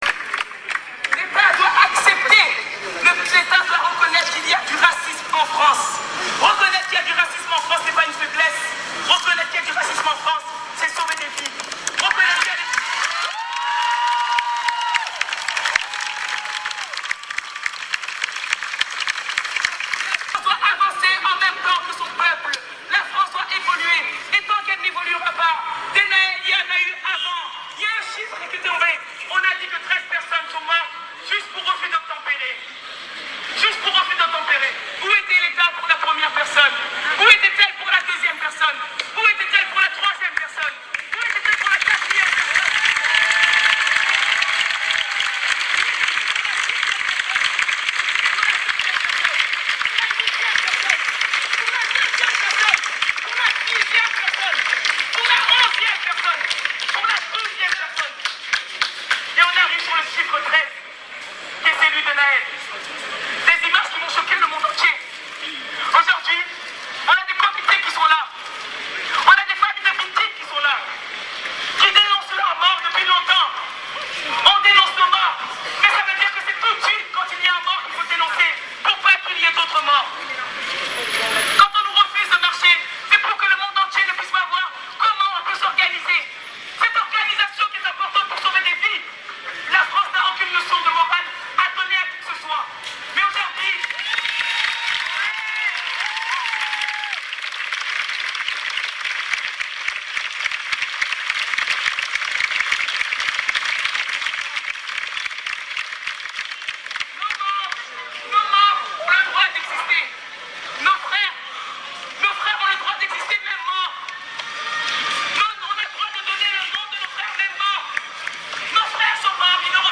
Assa Traoré prononce son discours du haut d’un abribus
Assa Traoré, du haut d’un abri bus, a tenu un discours poignant à la fin duquel elle a appelé les gens à rentrer chez eux dans le calme et la dignité : « nous avons sauvé l’honneur, le poing levé. », a-t-elle conclu avant de s’éclipser.
discours-assa-traore-ok.mp3